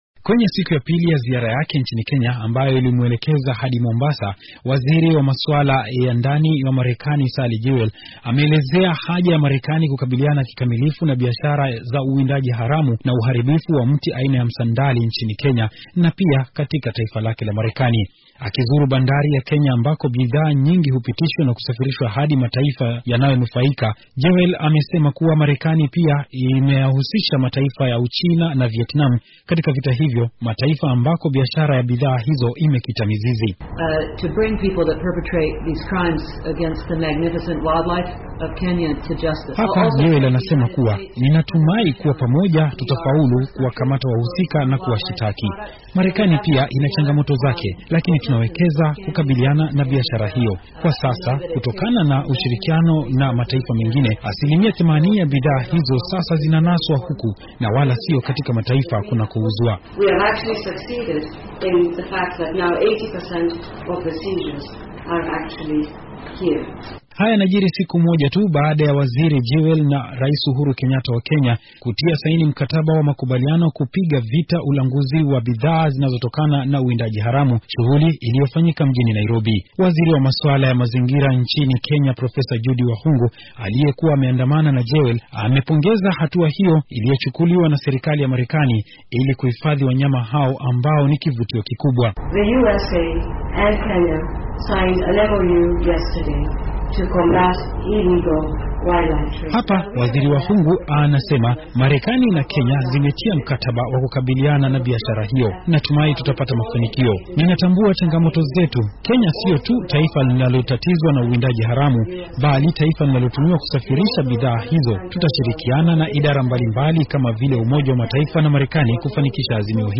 Ripoti ya mwandishi wetu